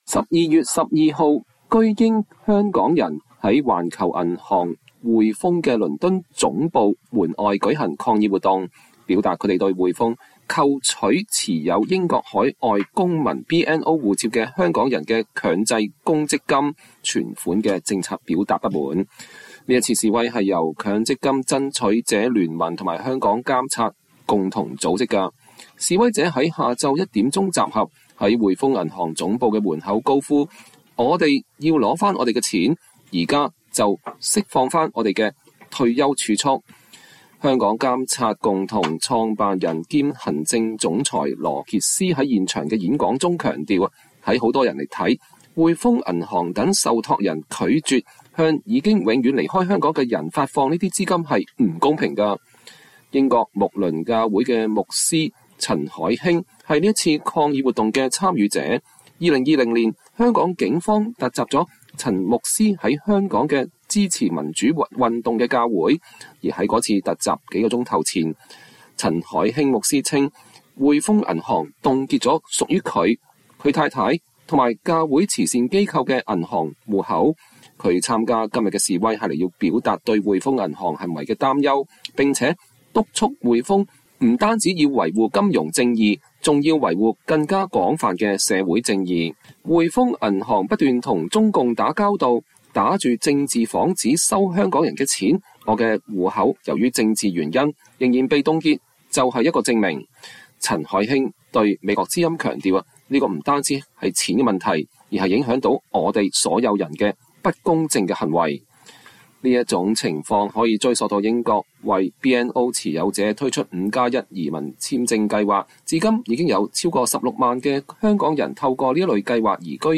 示威者們於下午一時集合，在匯豐銀行總部的門口高呼“我們想要回我們的錢，現在就釋放我們的退休儲蓄”。